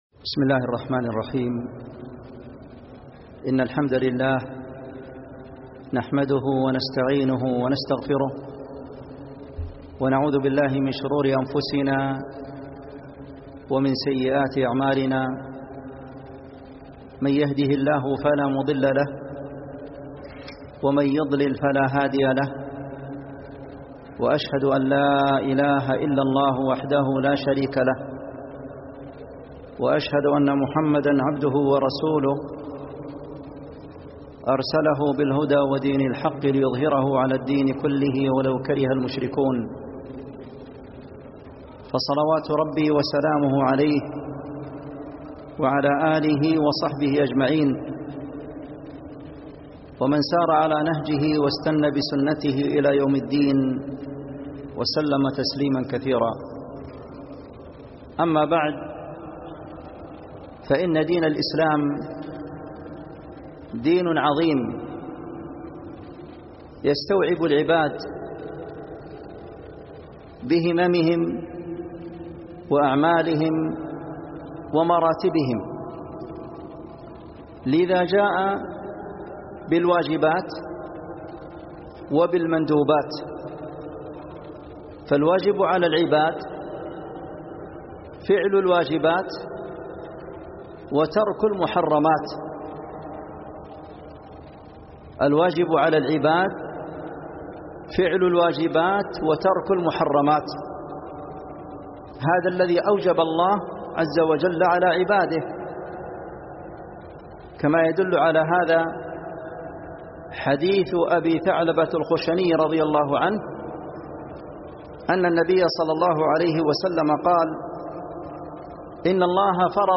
مسجد قباء سماع المحاضرة